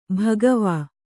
♪ bhagavā